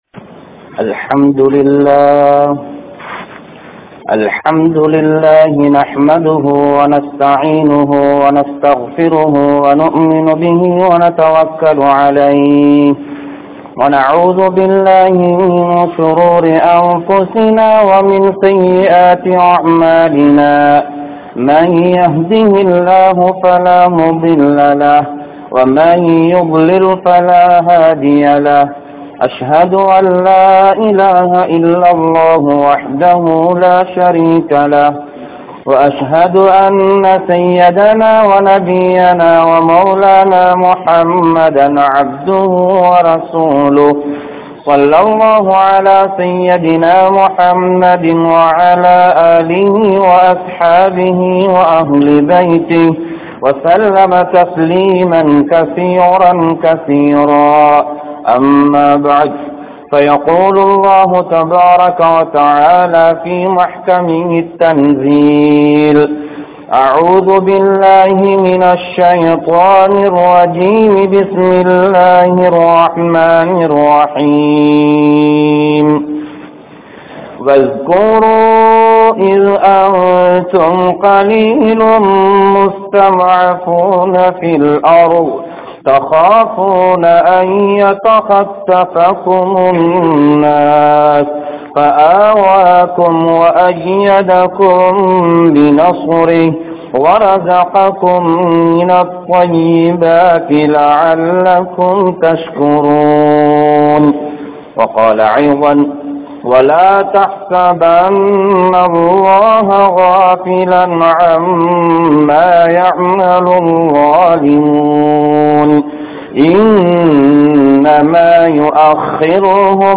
Neethamaana Aatsi Veanduma? (நீதமான ஆட்சி வேண்டுமா?) | Audio Bayans | All Ceylon Muslim Youth Community | Addalaichenai